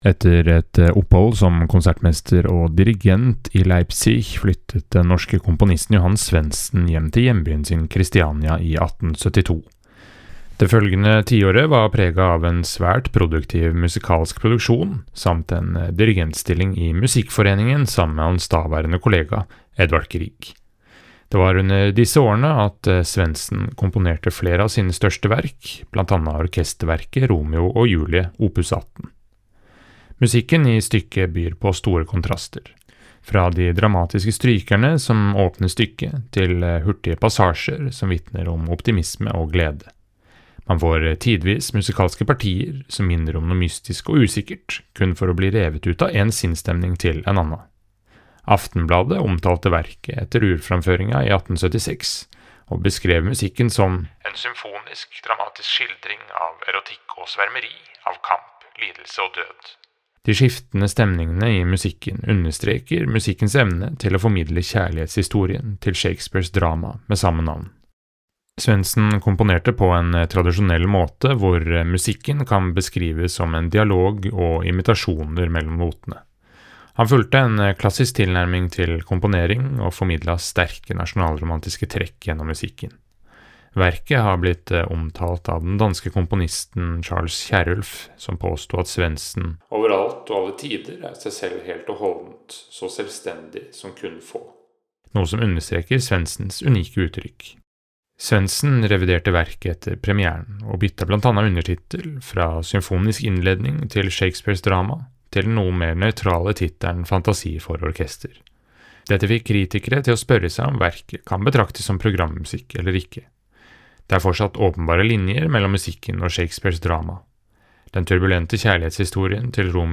VERKOMTALE-Johan-Svendsens-Romeo-og-Julie.mp3